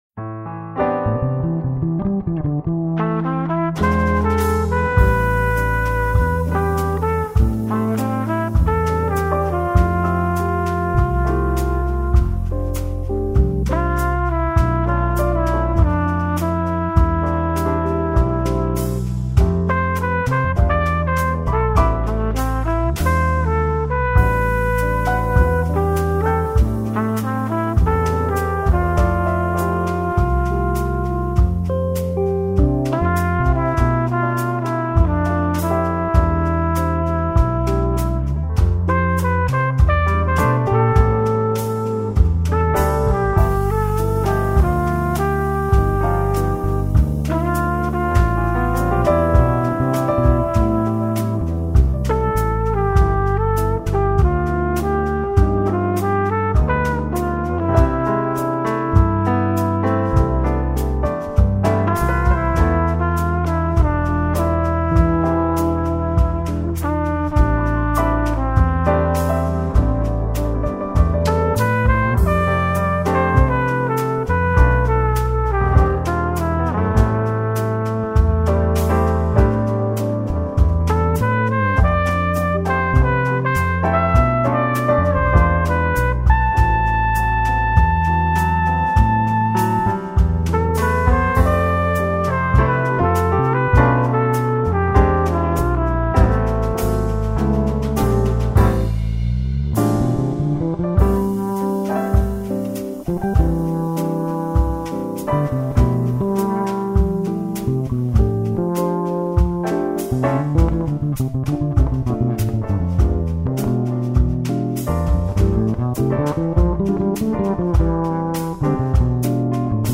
2538   06:11:00   Faixa: 4    Jazz